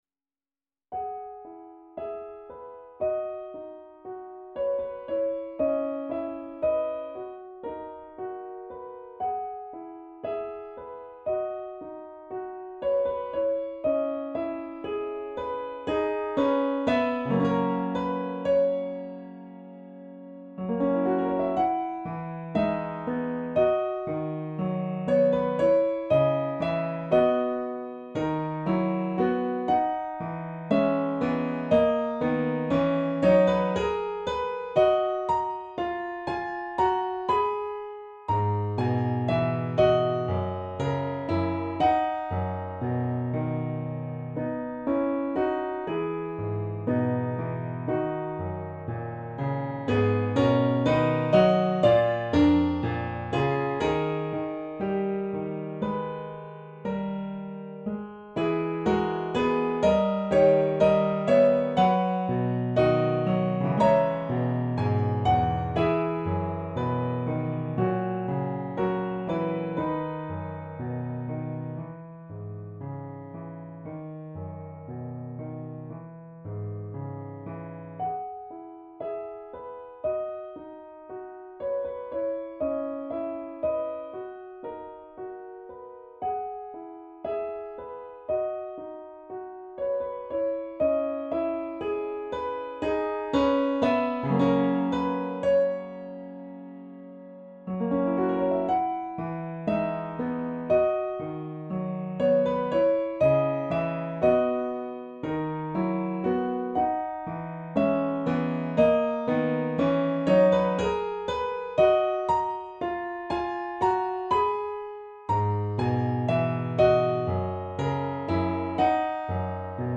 【ピアノ楽譜付き】